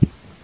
beeb kick 15
Tags: 808 drum cat kick kicks hip-hop